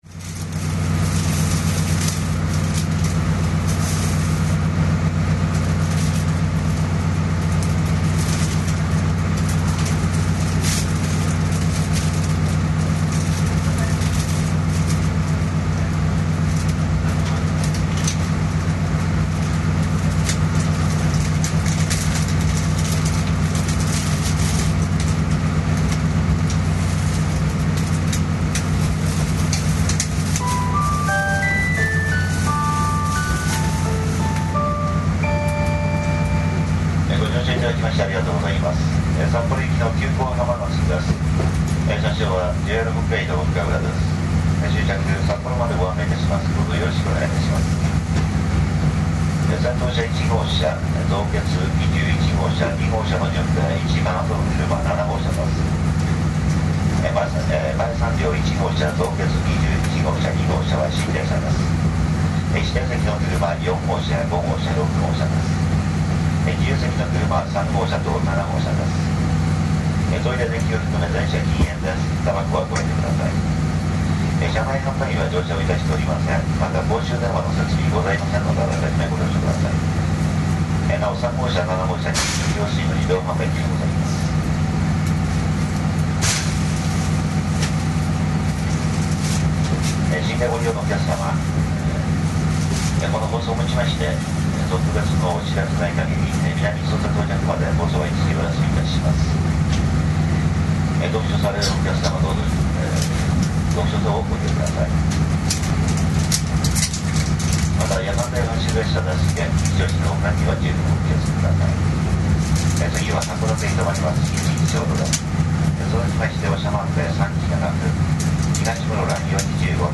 ＪＲ北海道　スハフ14-556　急行はまなす　青森→油川　2.68MB
味気ない電子チャイムになったのは大きなマイナスですが、風情ある客車急行（しかも夜汽車）の存在は何物にも代え難い価値があると感じます。ショックの無い客車の引き出し、そして車掌さんの肉声が何とも言えません。